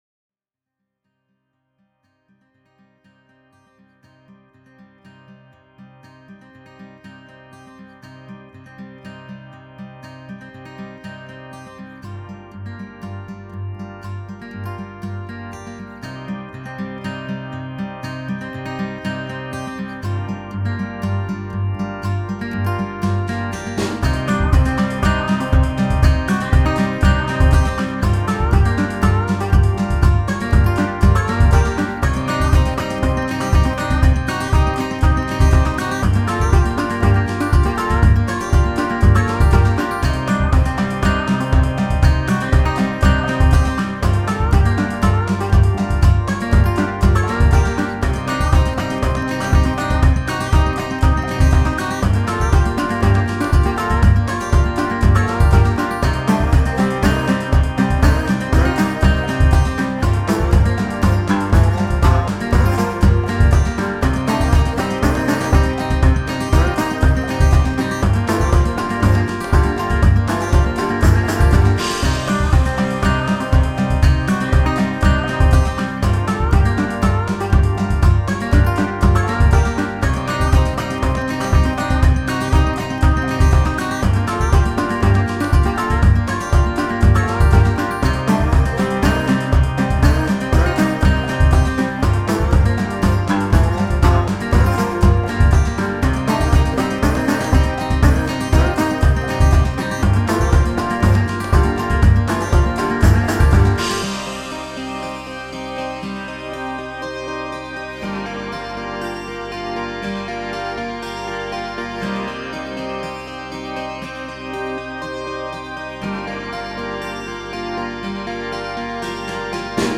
freestyle country